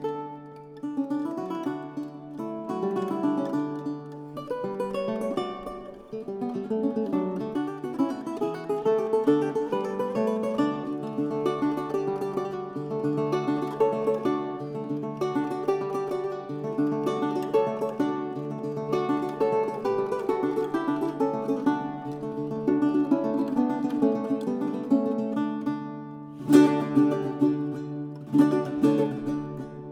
Жанр: Классика